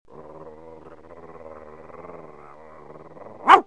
Dog Angry 1 Sound Button - Free Download & Play